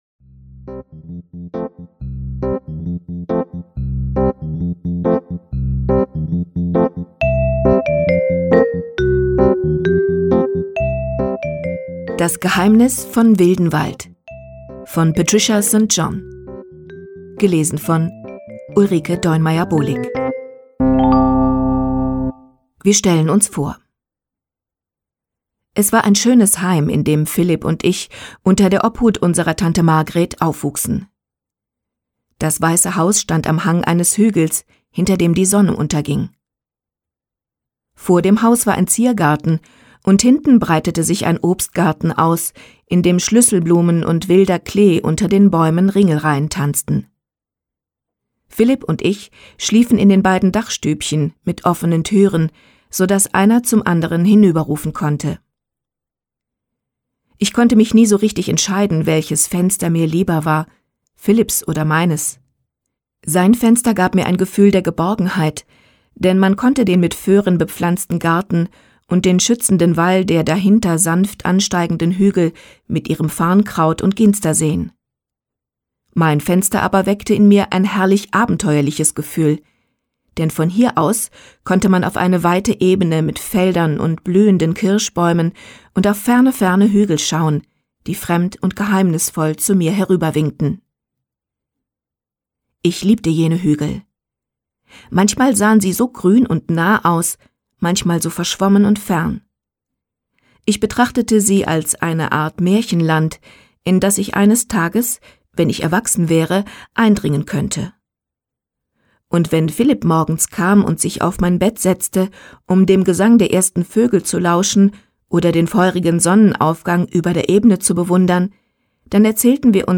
St. John: Das Geheimnis von Wildenwald (MP3-Hörbuch)
Inhalt Hörbuch